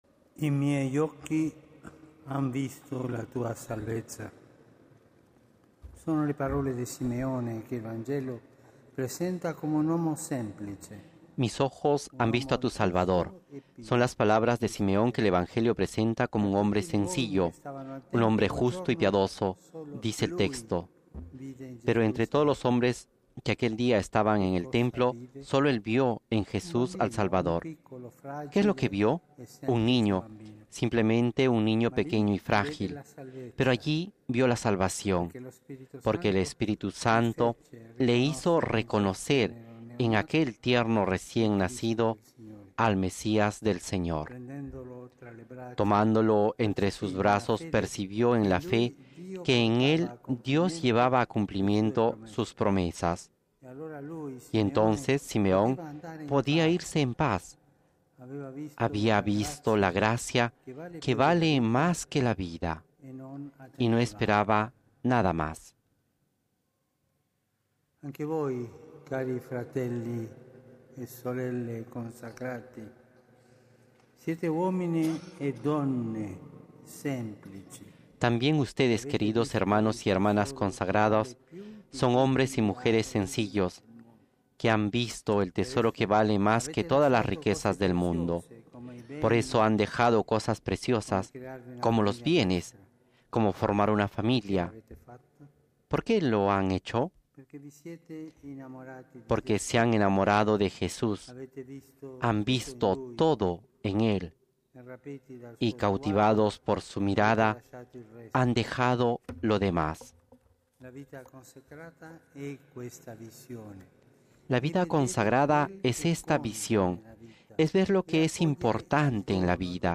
El Papa Francisco celebra la misa con los miembros de los Institutos de Vida Consagrada y las Sociedades de Vida Apostólica, con motivo de la XXIV Jornada Mundial de la Vida Consagrada